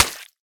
sounds / block / mud / break5.ogg